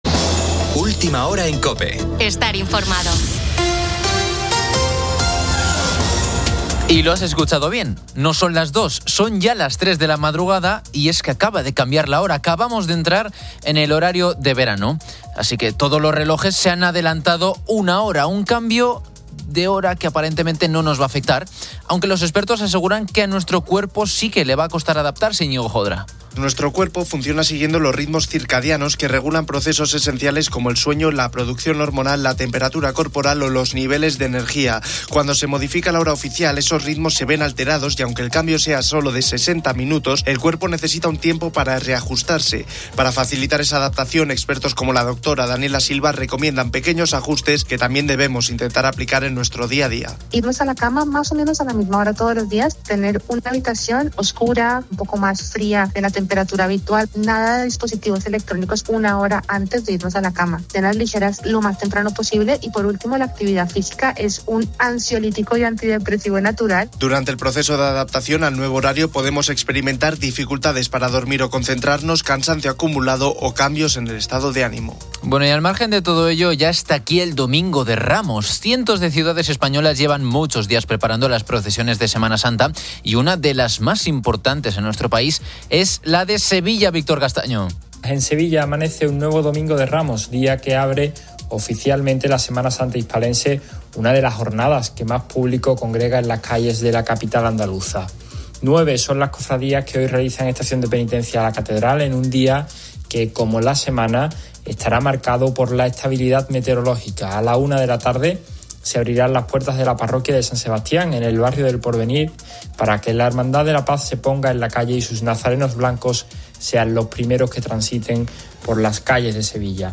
La actualidad deportiva aborda el Gran Premio de Japón de Fórmula 1 y el MotoGP, incluyendo una divertida simulación donde "Fernando Alonso" bromea sobre su carrera en Suzuka y sus rivales.